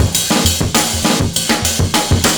100CYMB09.wav